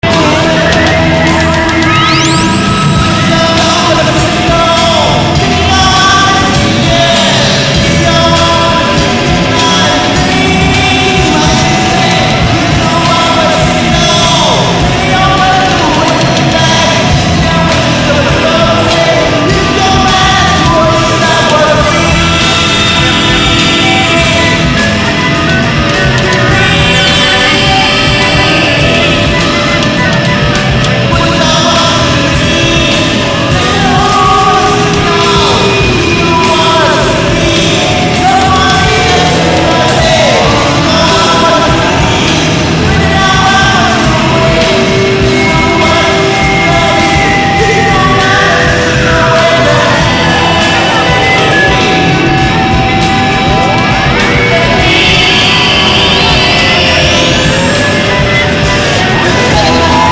uilleann pipe